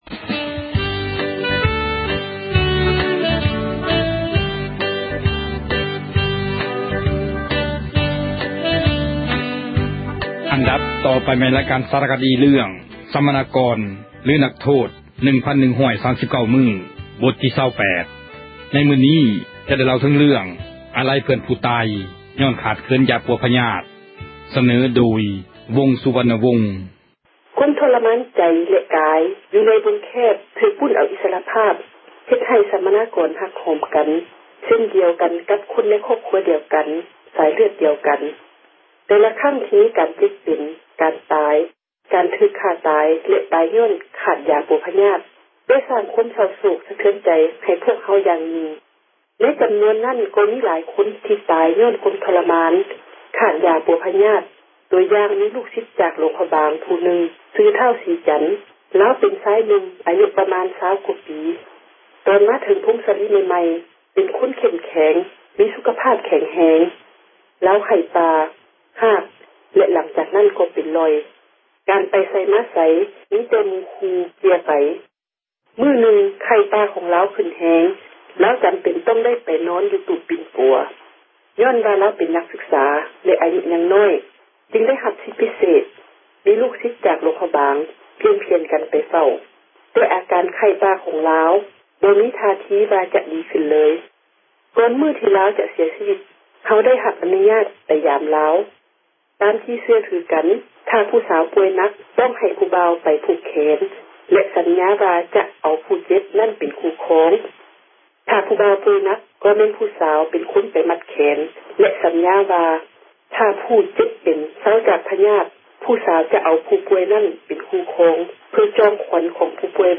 ອັນດັບ ຕໍ່ໄປ ແມ່ນ ຣາຍການ ສາຣະຄະດີ ເຣື້ອງ ”ສັມມະນາກອນ ຫຼື ນັກໂທດ 1,139 ມື້“ ບົດທີ 28. ໃນມື້ນີ້ ຈະໄດ້ ກ່າວເຖິງ ເຣື້ອງ ອາລັຍ ເພື່ອນ ຜູ້ຕາຍ ຍ້ອນຂາດຢາ ປົວພຍາດ.